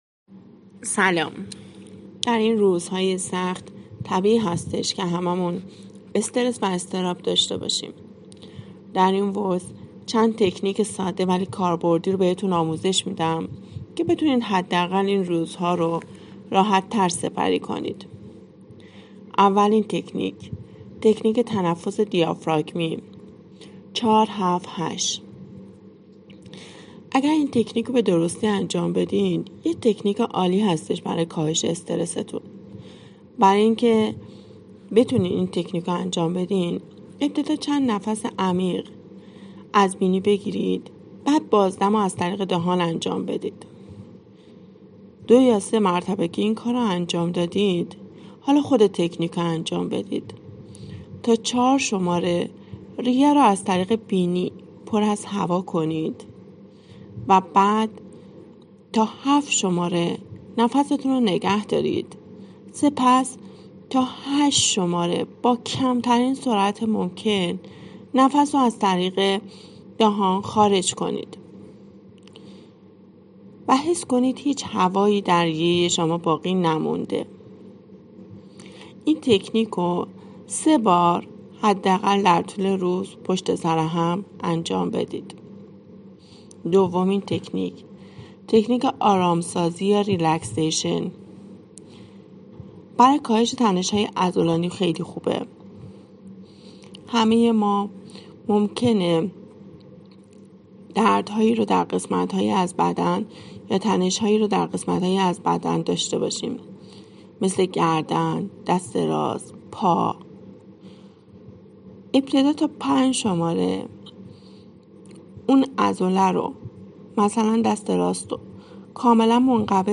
پادکست صوتی: